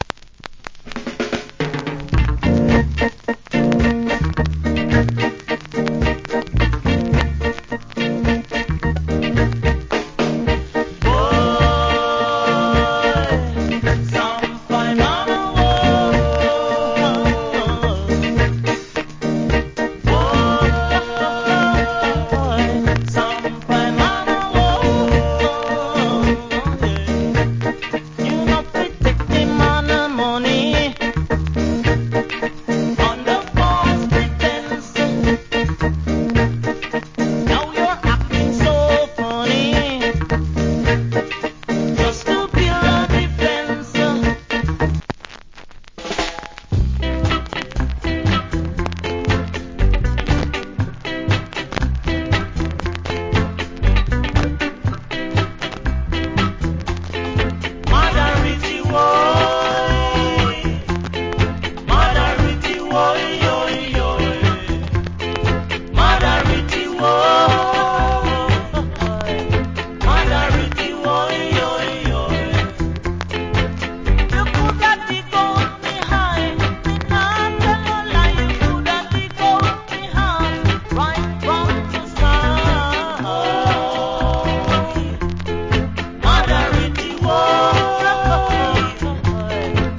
Nice Early Reggae Vocal.